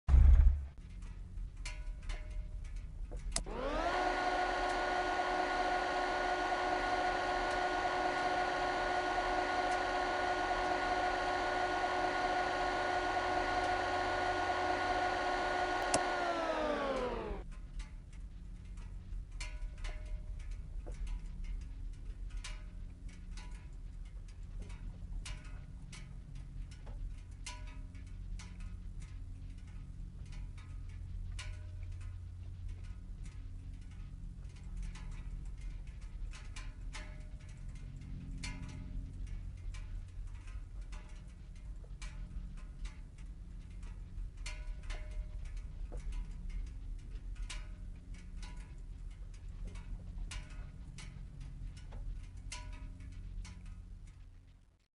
engine_off.mp3